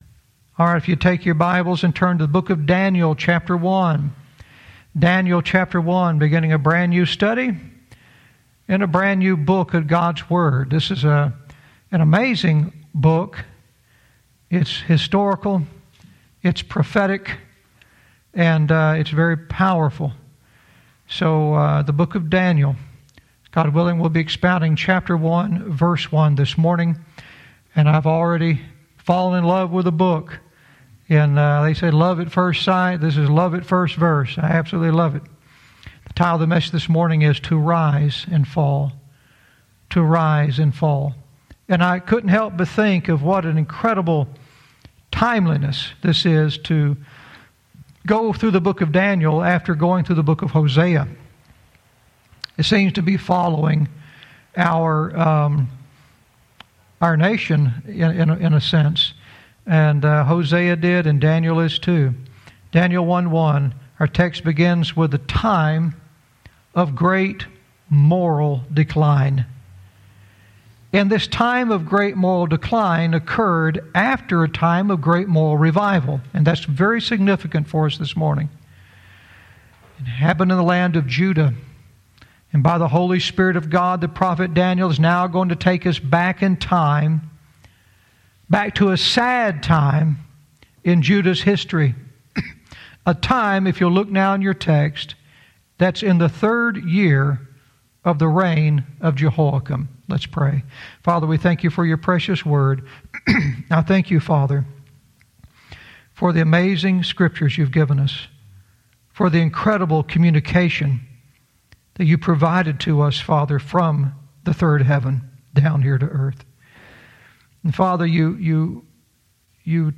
Verse by verse teaching - Daniel 1:1 "To Rise and Fall"